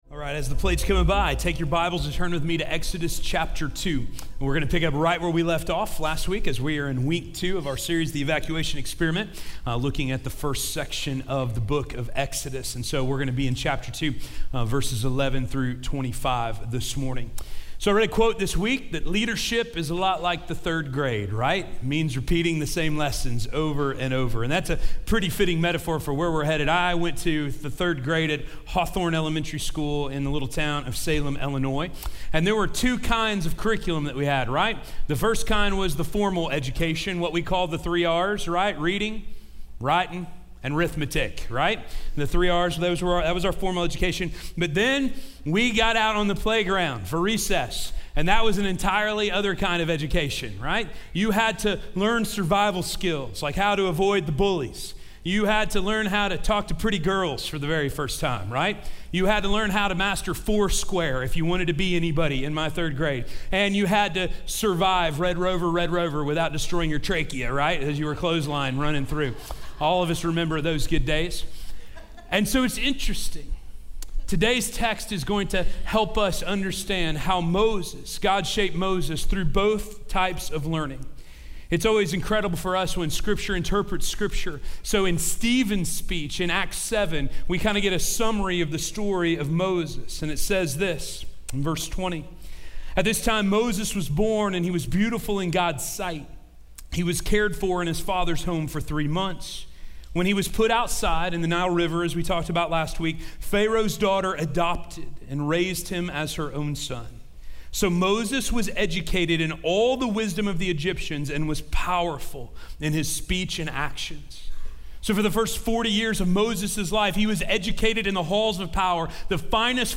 The Failed Evacuation: Moses Murders - Sermon - Station Hill